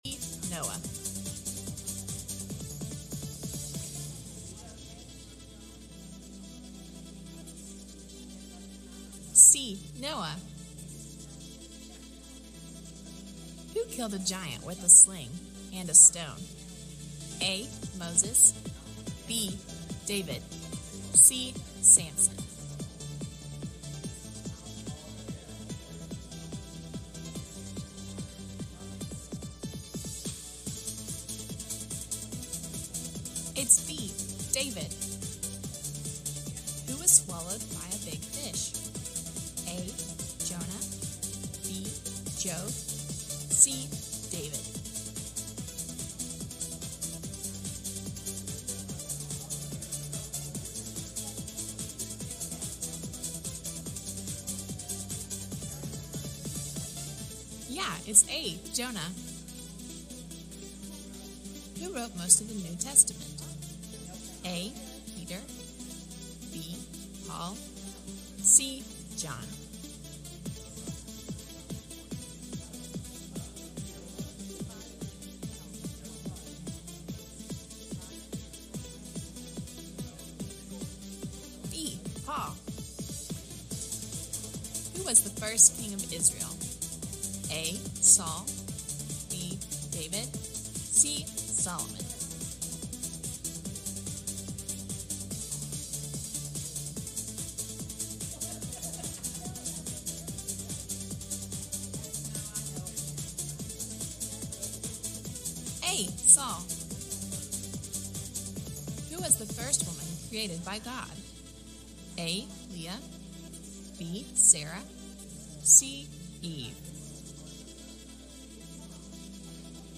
Message Service Type: Midweek Meeting « How A Mother Fights The Devil